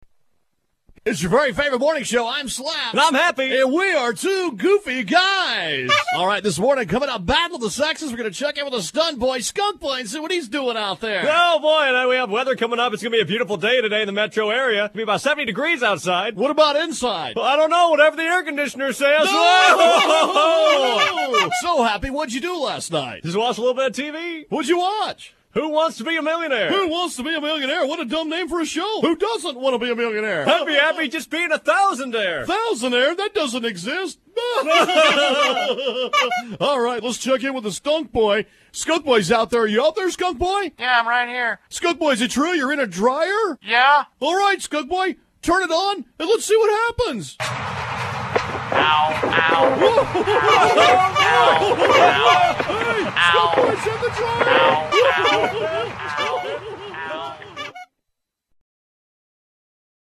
comedy radio skits and comedy music